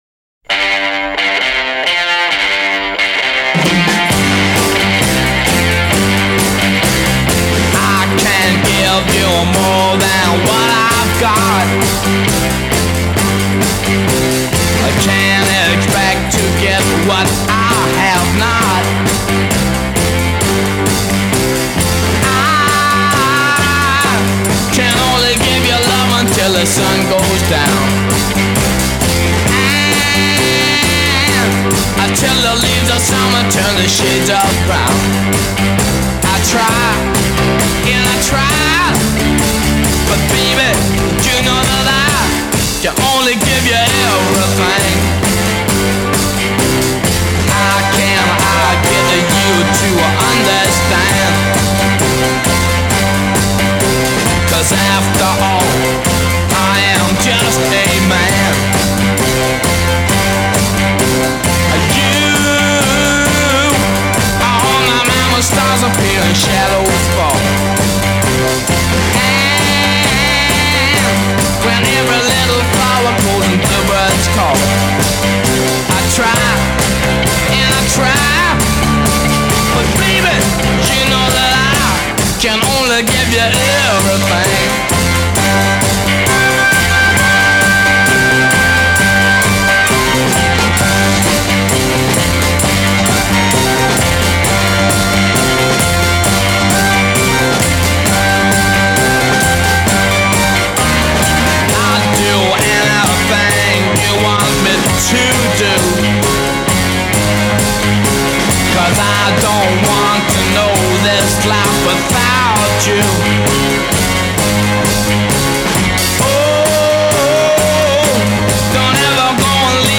thumping caveman rhythm and blues with a snap and a snarl
The whole thing kicks like a mule.